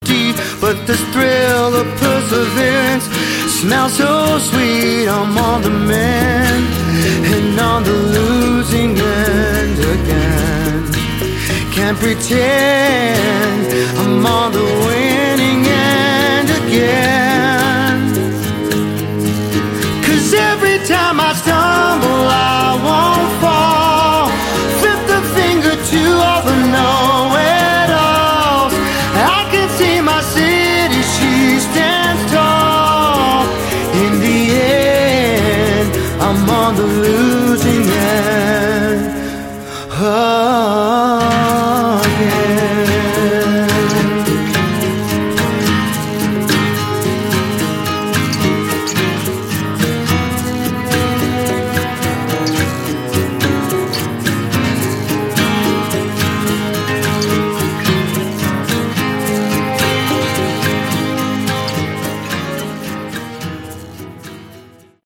Category: Hard Rock
vocals, guitar
bass
drums